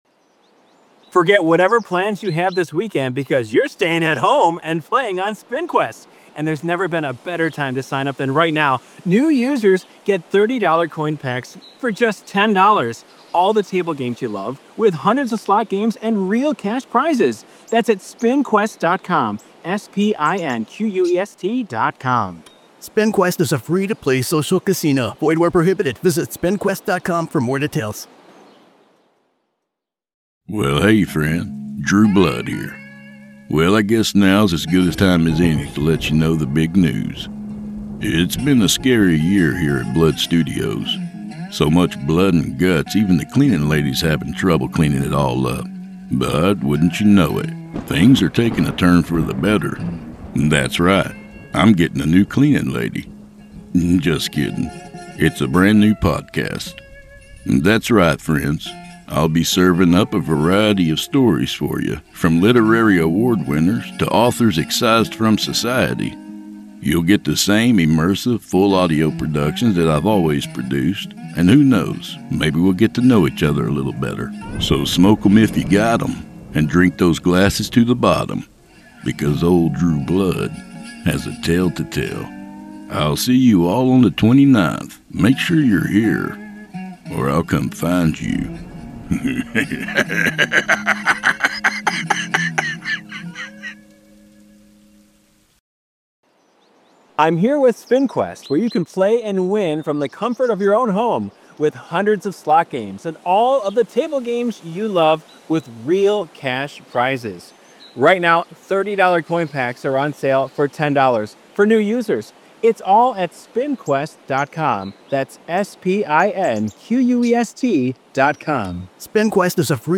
With immersive, full-production, and an indelible flavor all his own, you'll hear the finest horror in captivating fashion.